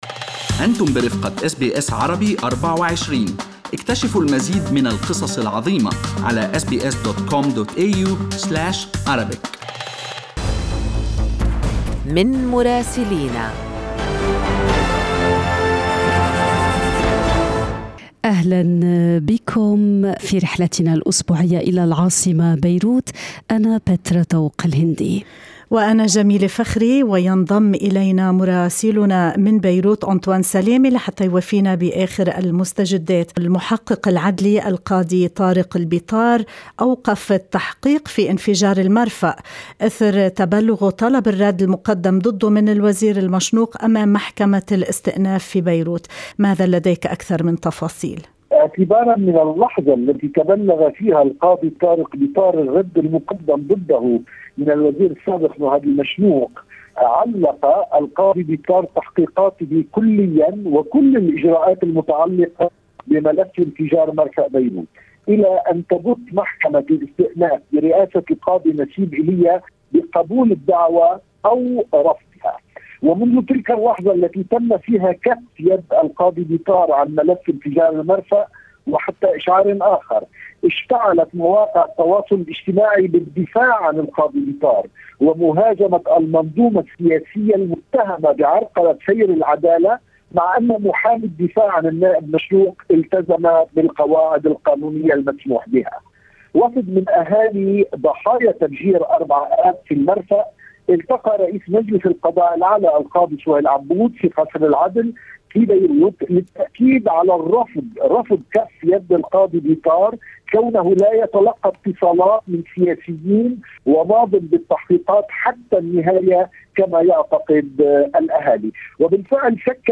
من مراسلينا: أخبار لبنان في أسبوع 28/9/2021